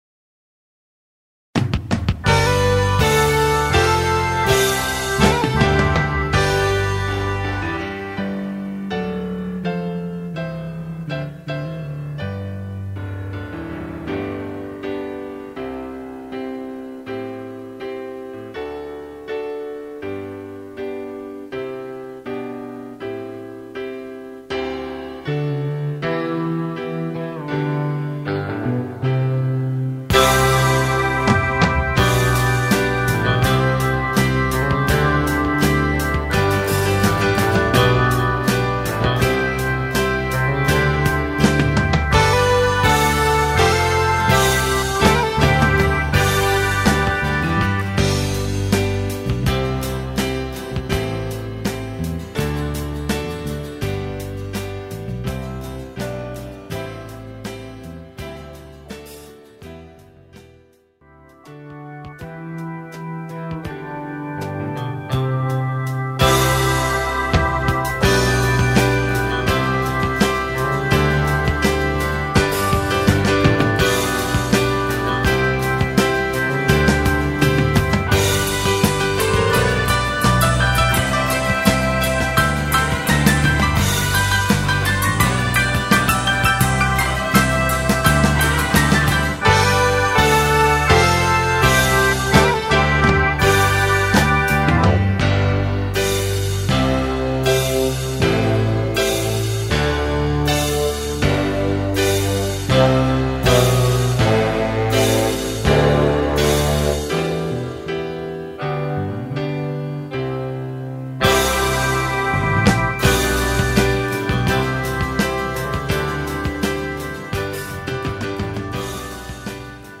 Gospel Rock